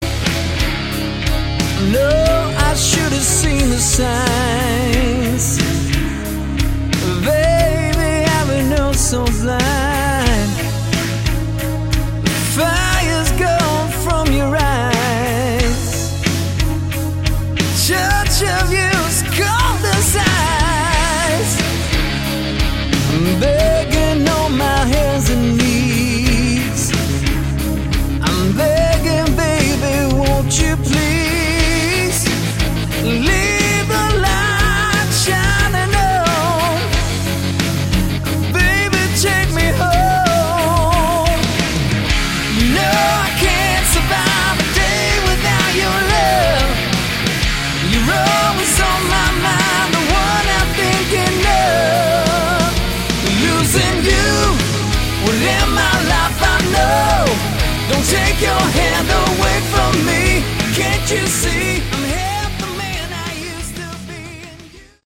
Category: AOR
bass guitar, guitar, vocals
drums, keyboards
lead guitar